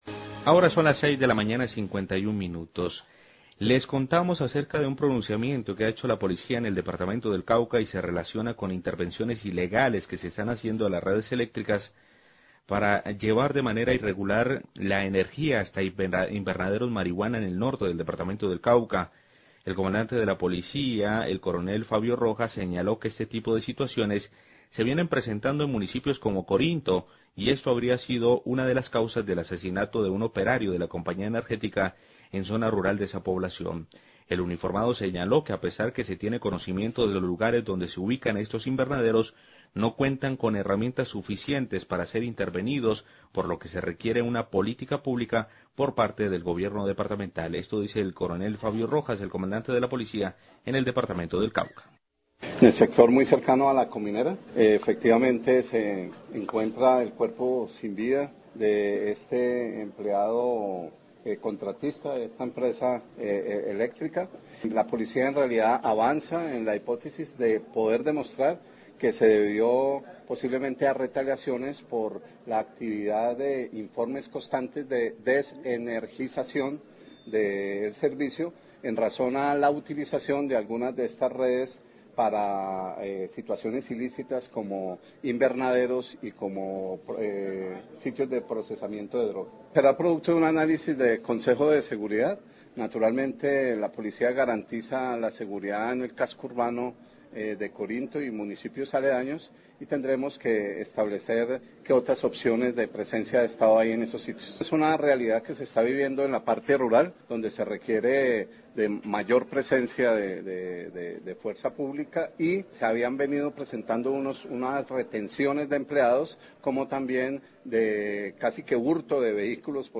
Radio
Las redes de energía están siendo violentadas para conducir la energía hasta los invernaderos de marihuana en el Norte del Cauca, esta habría sido la causa del asesinato de un operario de la Compañía Energética en Corinto. Declaraciones del Coronel Fabio Rojas, Comandante de la Policía Cauca.